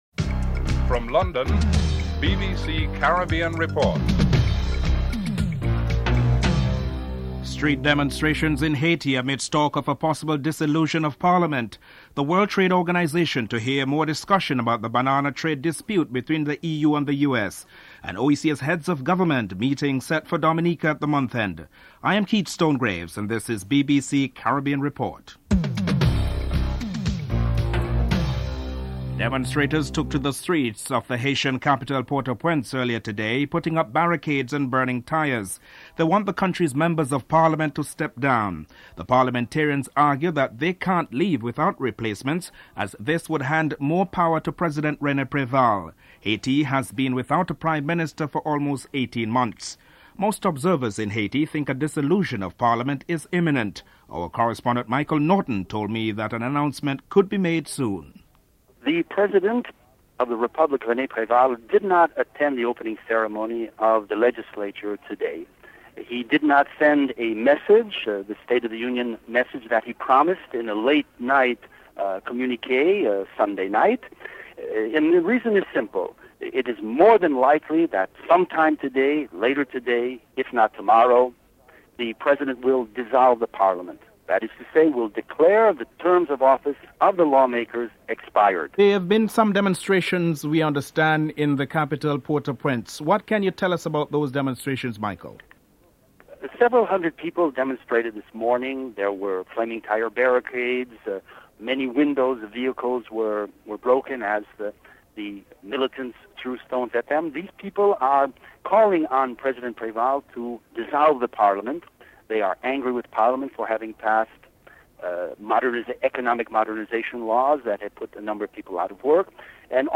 dc.formatStereo 192 bit rate MP3;44,100 Mega bits;16 biten_US
dc.typeRecording, oralen_US